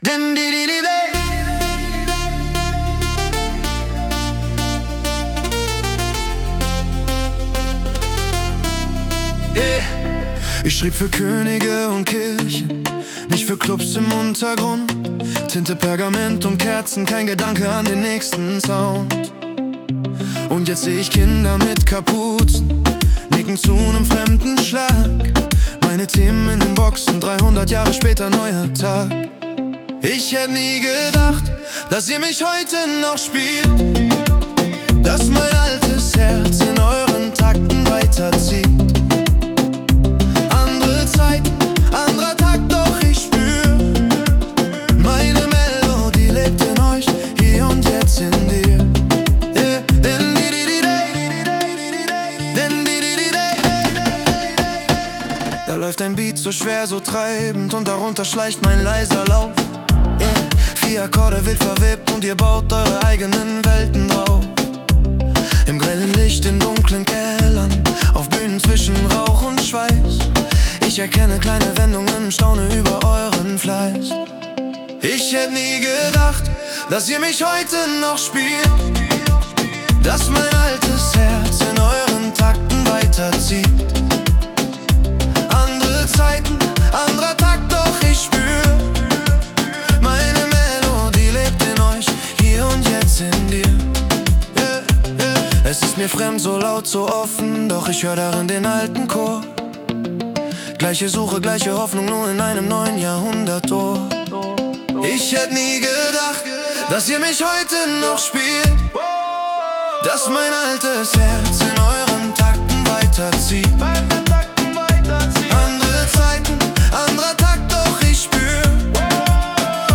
(Musikalische Inhalte wurden teilweise mit KI-Unterstützung generiert)